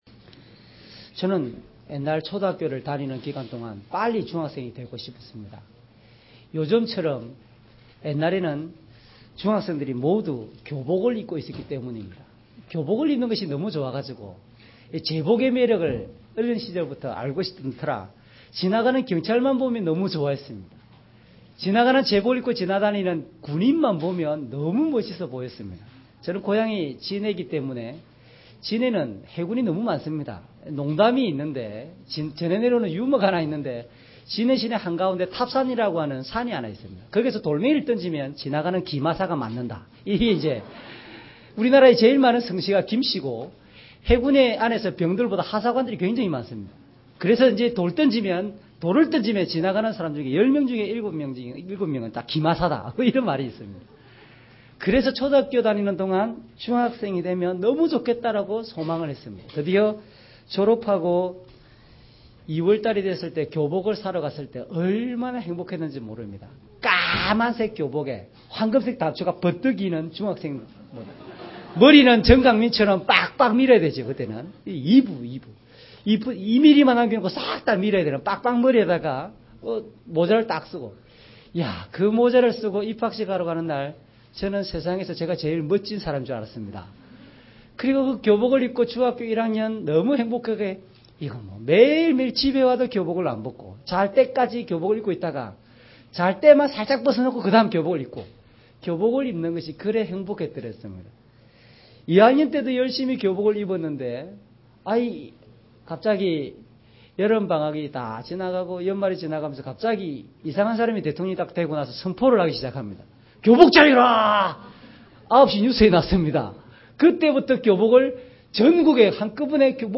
주일설교 - 10년 08월 22일 "우리는 새언약의 백성들입니다."